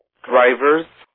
Ääntäminen
Ääntäminen US Haettu sana löytyi näillä lähdekielillä: englanti Käännöksiä ei löytynyt valitulle kohdekielelle. Drivers on sanan driver monikko.